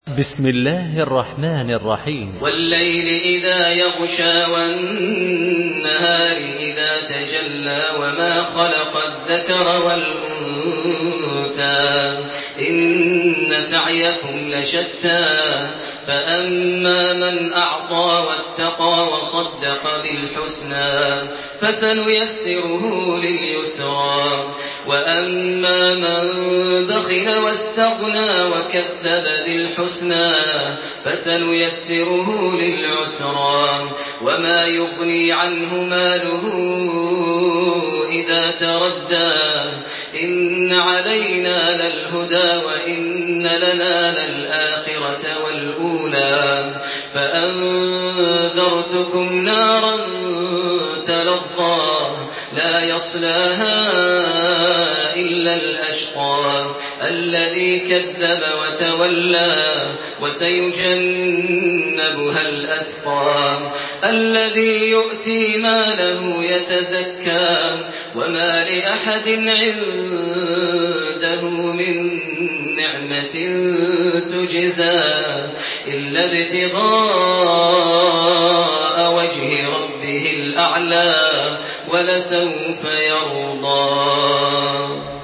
Surah الليل MP3 by تراويح الحرم المكي 1428 in حفص عن عاصم narration.
مرتل